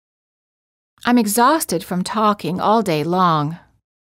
• ストレスをかける単語の主要な母音を長く発音する
※当メディアは、別途記載のない限りアメリカ英語の発音を基本としています